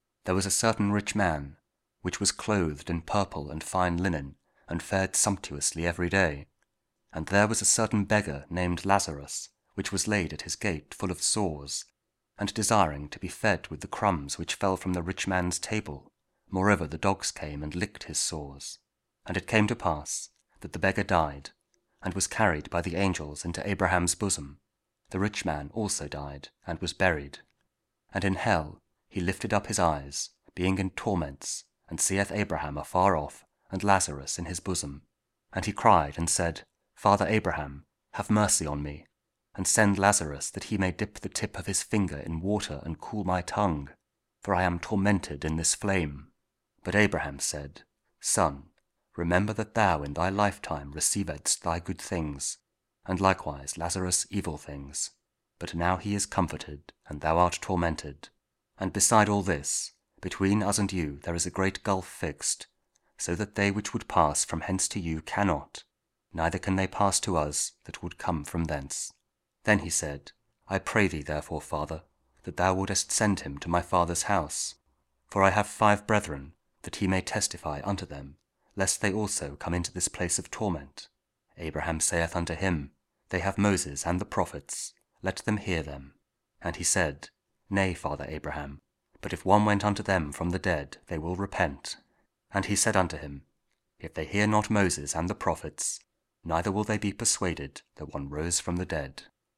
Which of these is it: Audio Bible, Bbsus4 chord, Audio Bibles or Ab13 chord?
Audio Bible